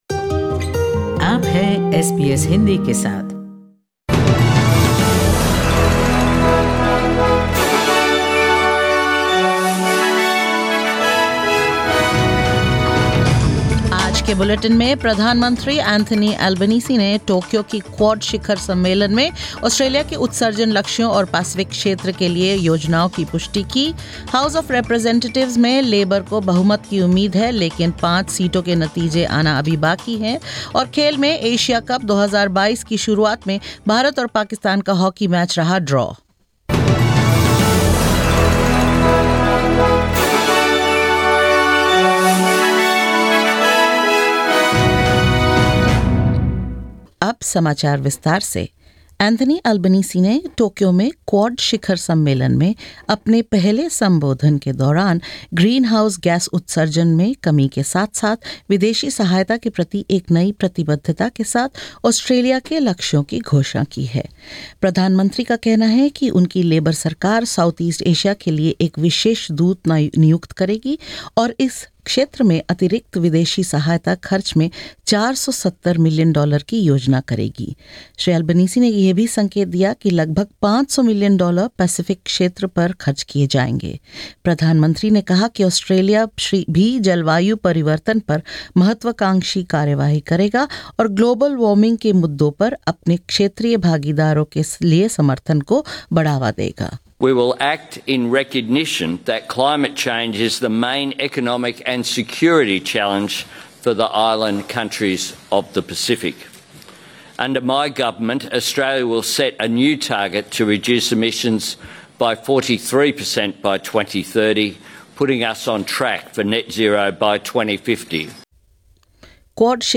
In this latest SBS Hindi bulletin: Prime Minister confirms Australia's emissions target plans for the Pacific at Tokyo's Quad meet; Vote count continues with some seats too close to call for Labor majority in the House of Representatives; New South Wales could soon offer free flu vaccines and more.